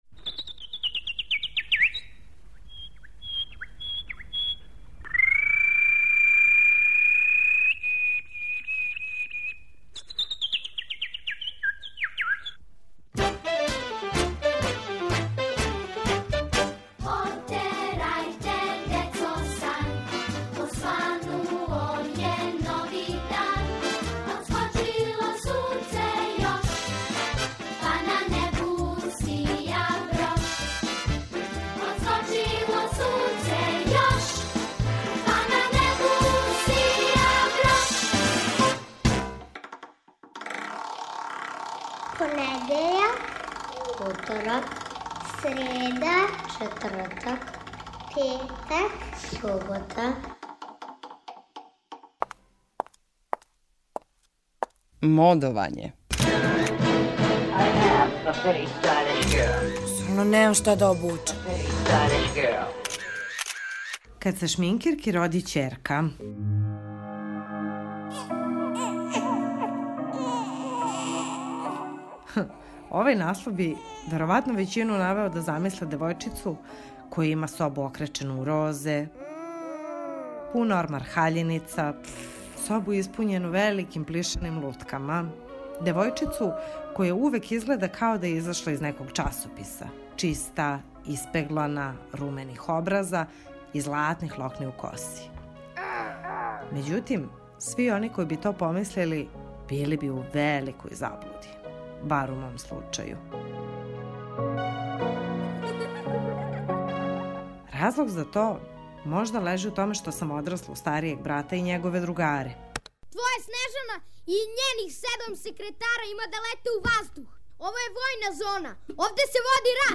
У серијалу о моди, једна професионална шминкерка прича о првом шминкању своје ћерке.